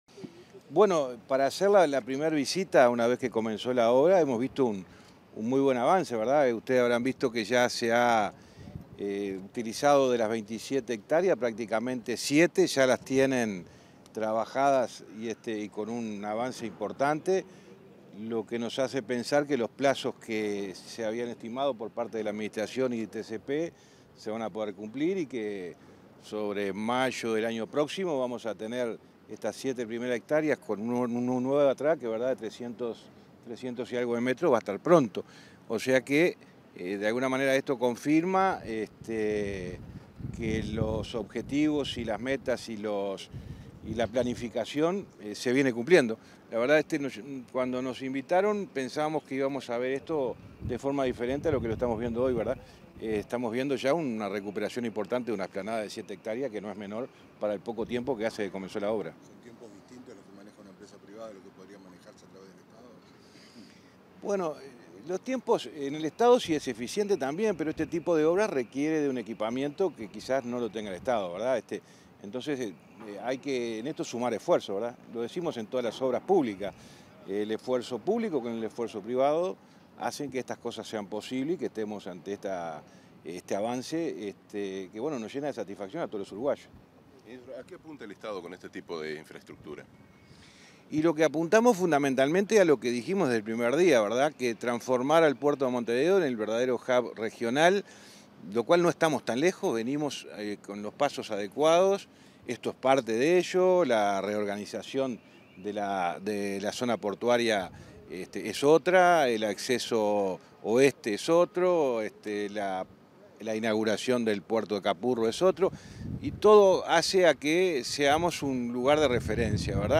Declaraciones del ministro de Transporte y Obras Públicas, José Luis Falero
Declaraciones del ministro de Transporte y Obras Públicas, José Luis Falero 05/02/2024 Compartir Facebook X Copiar enlace WhatsApp LinkedIn Tras una recorrida por las obras que se realizan en el puerto de Montevideo junto a representantes de la empresa Katoen Natie, este 5 de febrero, el ministro de Transporte y Obras Públicas, José Luis Falero realizó declaraciones a la prensa.